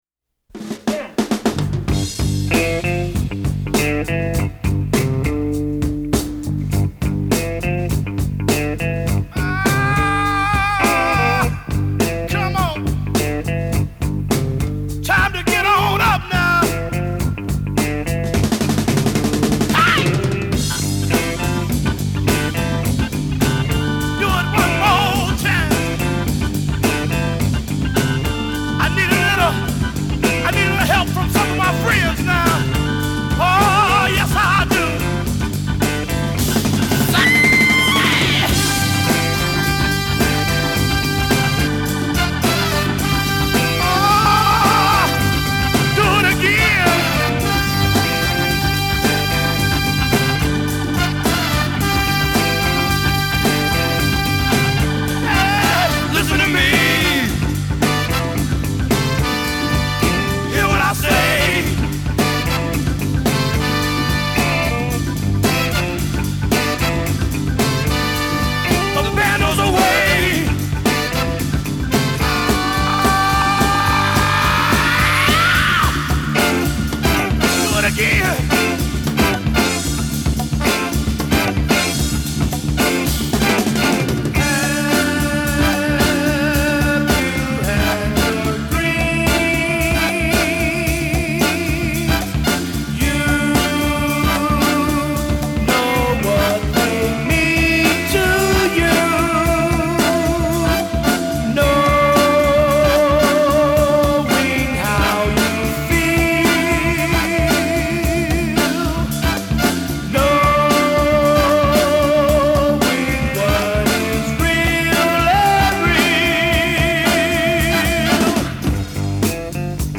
Beginning with a taught guitar riff